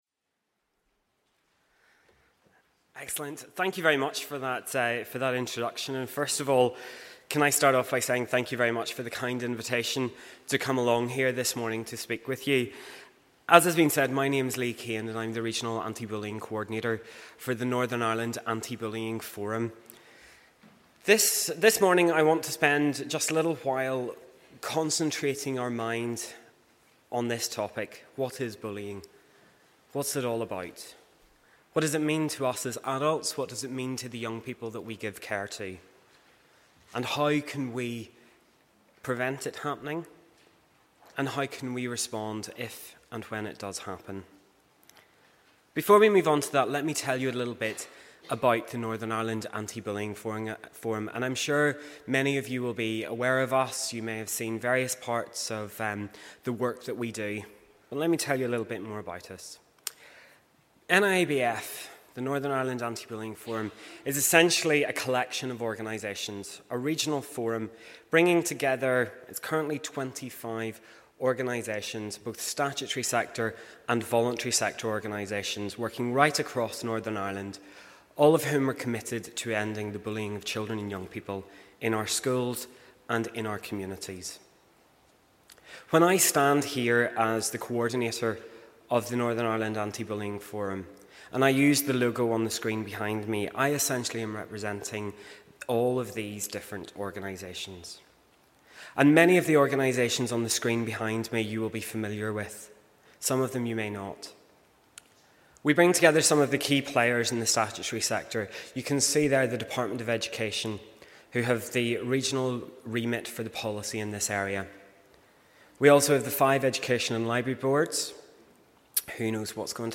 On the morning of Saturday 12th April 300 Designated Persons, Ministers, youth leaders and parents all gathered at assembly buildings to enjoy fantastic cupcakes together but more importantly than that, to consider some difficult issues and learn from experts across various fields.